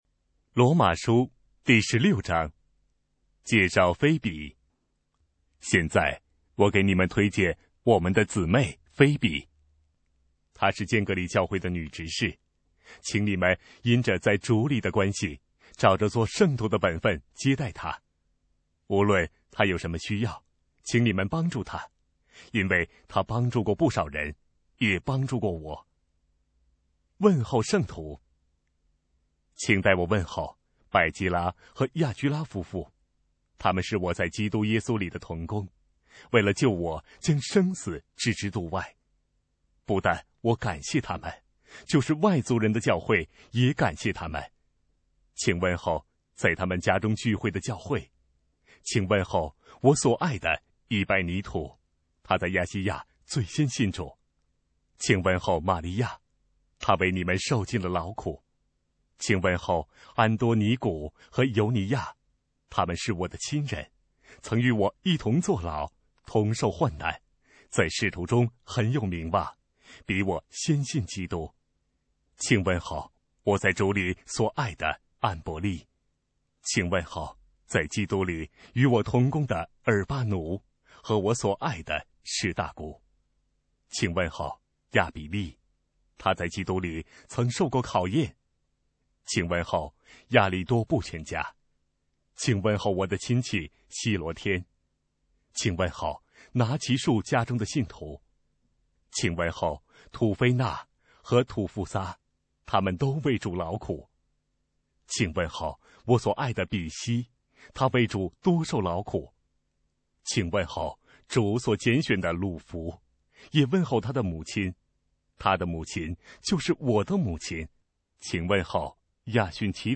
当代译本朗读：罗马书